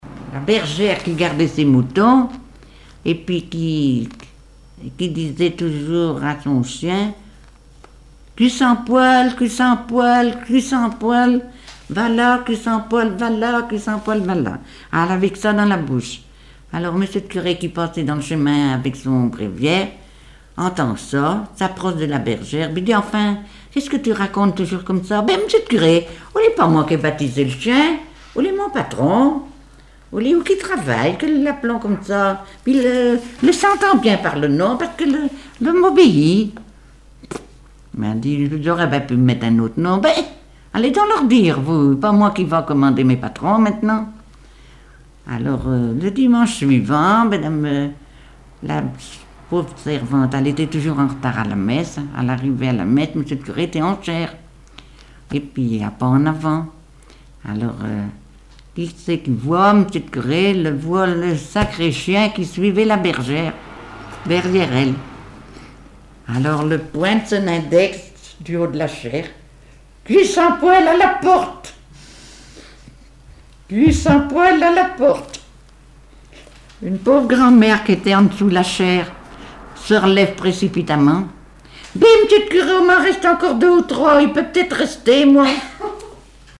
Genre sketch
Témoignages et chansons
Catégorie Récit